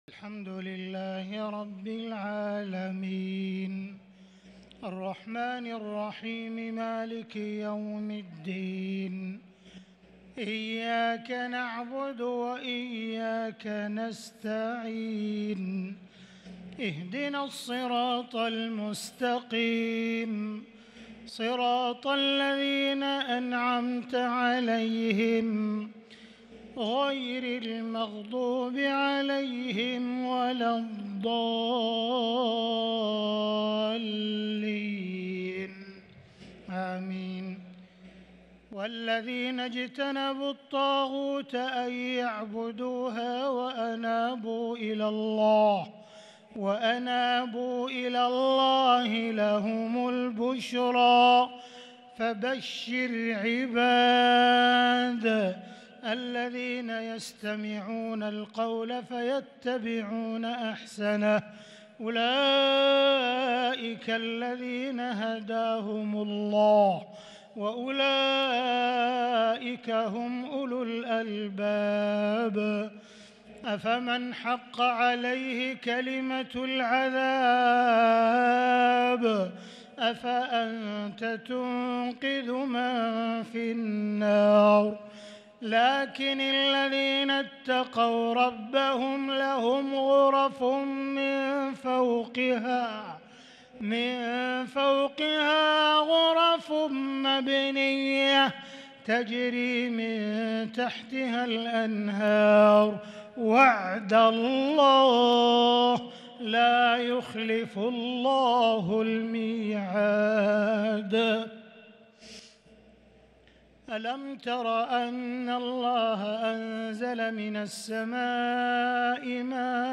تهجد ليلة 25 رمضان 1443هـ من سورة الزمر (17-40) | Tahajjud 25st night Ramadan 1443H Surah Az-Zumar > تراويح الحرم المكي عام 1443 🕋 > التراويح - تلاوات الحرمين